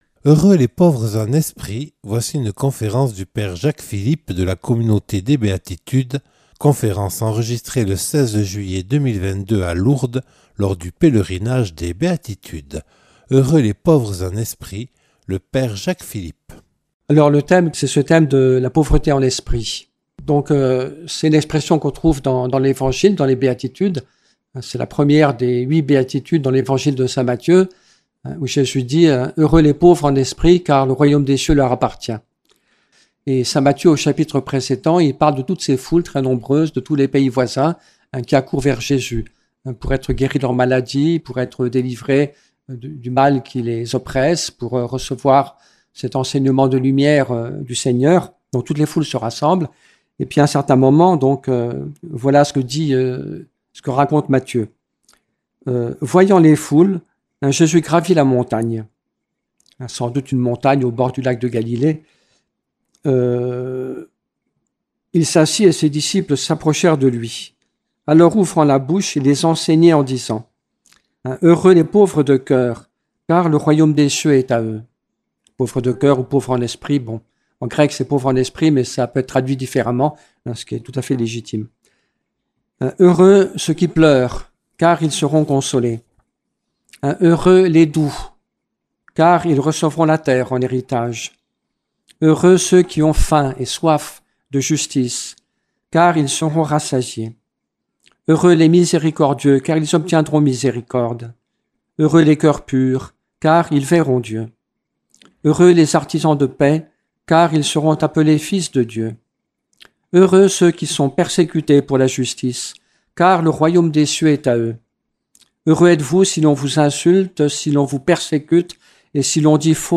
(Enregistré le 16/07/2022 à Lourdes lors du Pèlerinage des Béatitudes).